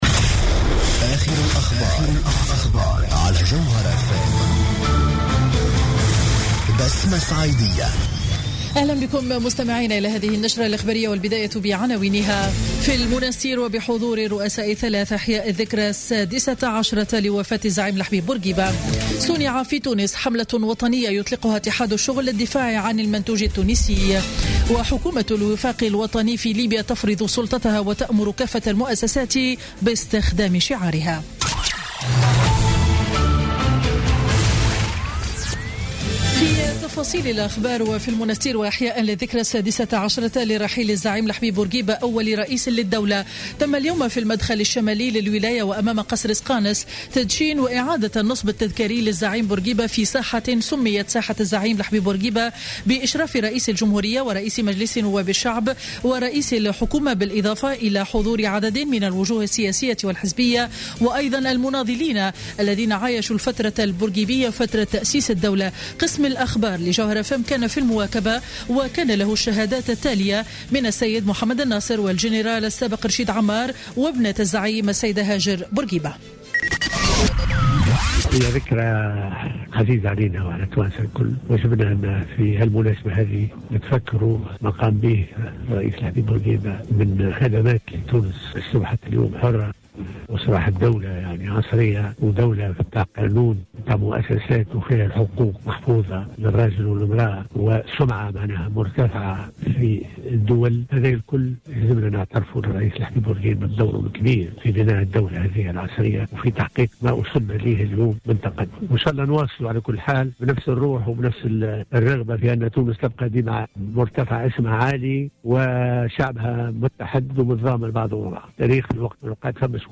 نشرة أخبار منتصف النهار ليوم الأربعاء 6 أفريل 2016